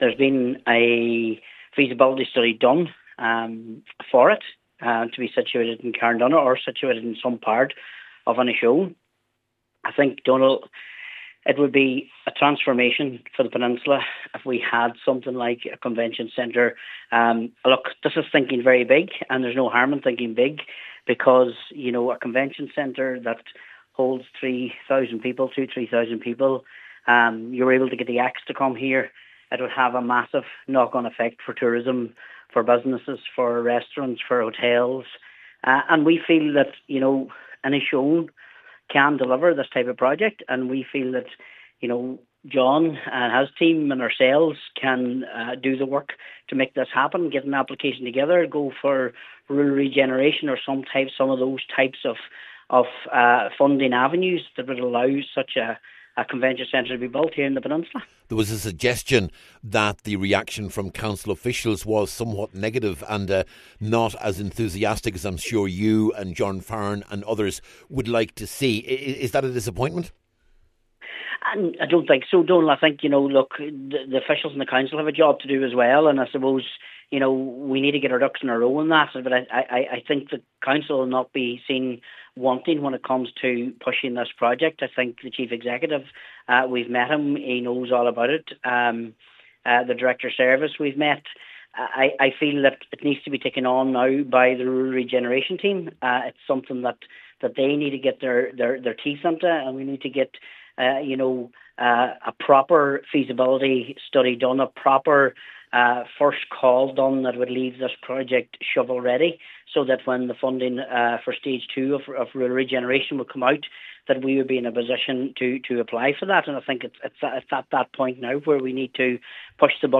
Cathaoirleach Cllr Martin McDermott told the meeting this would rival similar centres in Castlebar and Killarney, and give Inishowen the lift it needs………….